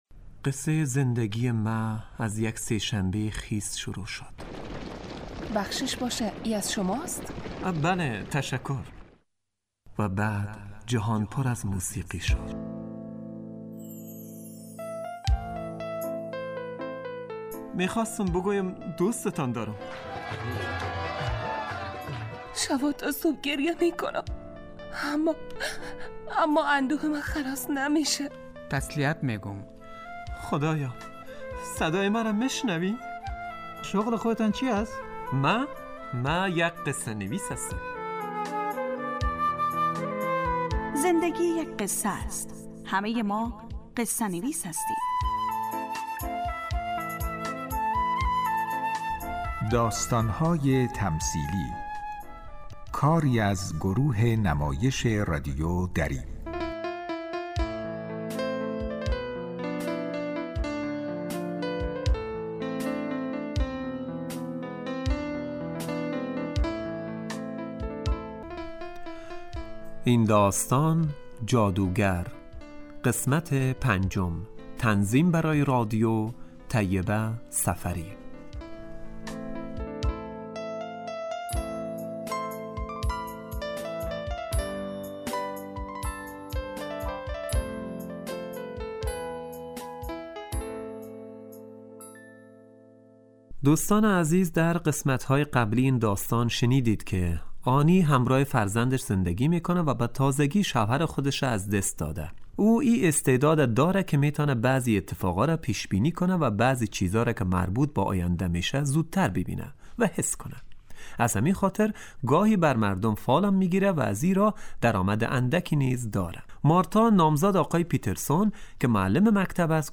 داستانهای تمثیلی نمایش 15 دقیقه ای هستند که روزهای دوشنبه تا پنج شنبه ساعت 03:25عصربه وقت افغانستان پخش می شود.